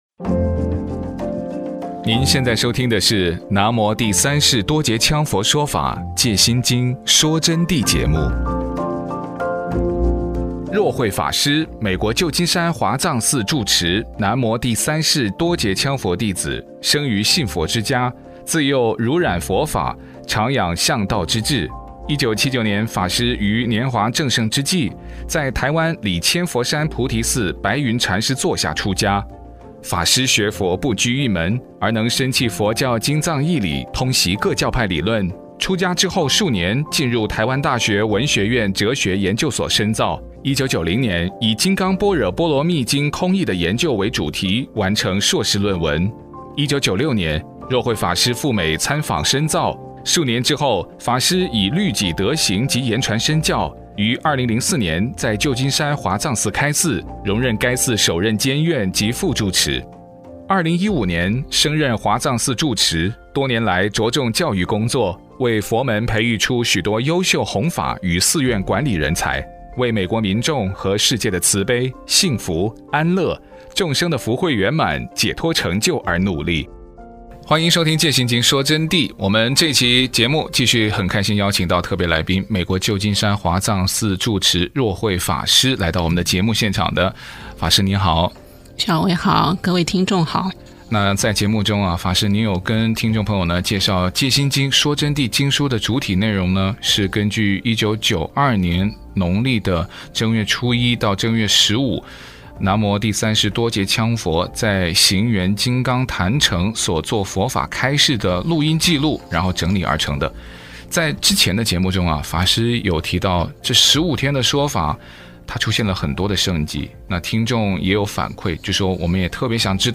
佛弟子访谈（七十六）